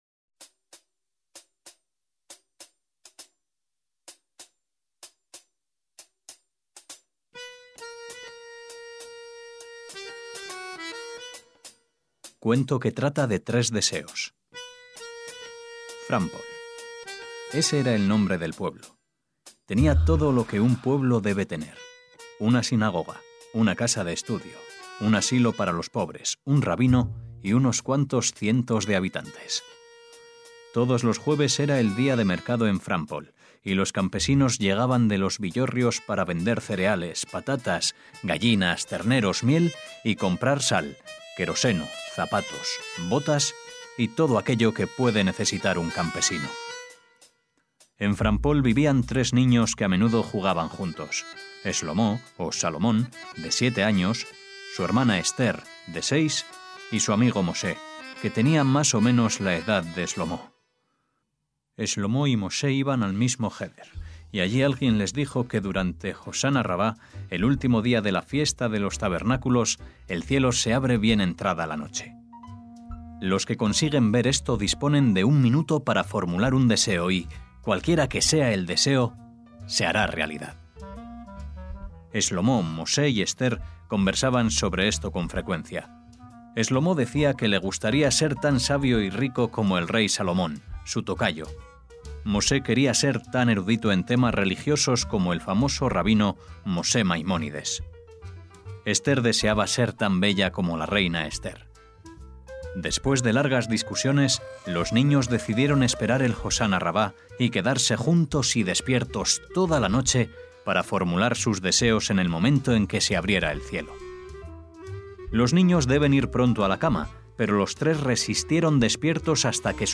CUENTOS PARA NIÑOS DE ISAAC BASHEVIS SINGER - Seguimos con la lectura de una historia de Isaac Bashevis Singer, nacido en Radzymin, Polonia, en 1904.